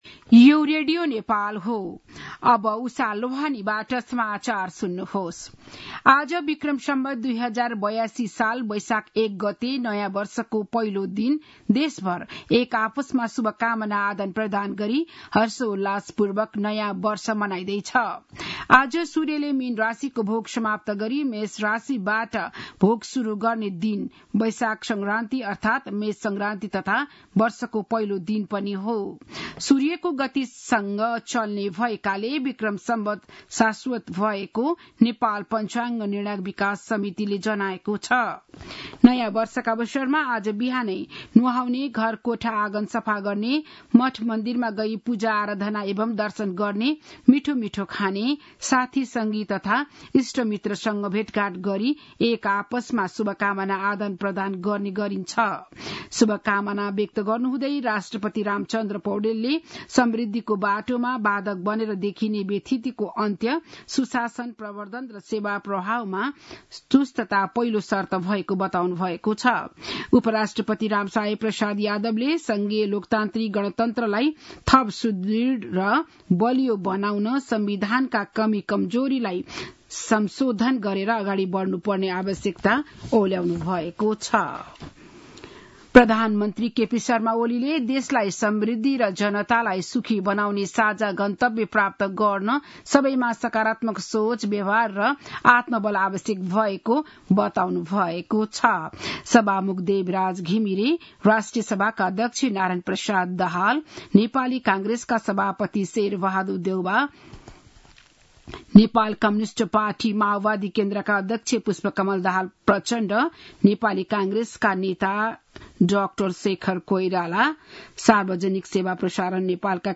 बिहान ११ बजेको नेपाली समाचार : १ वैशाख , २०८२
11-am-news.mp3